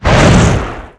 NapalmeHit3.wav